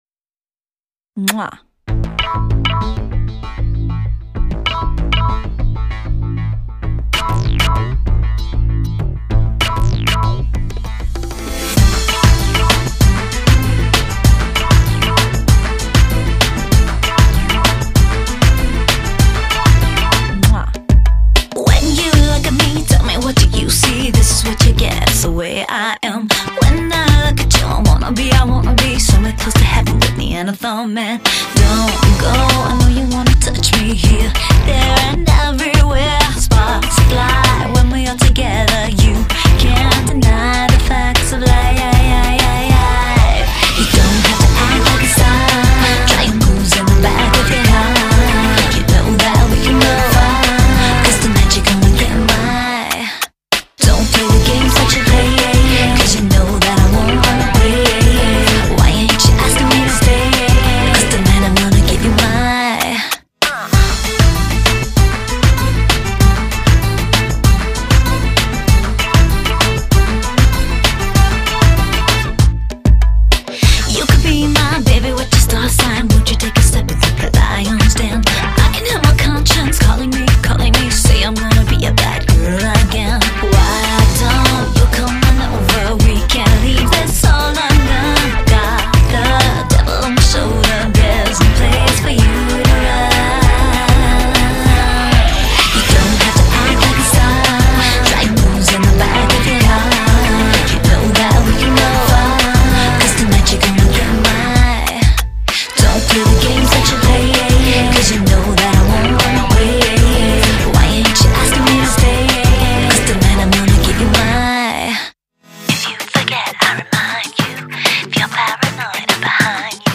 专辑流派： Electronic House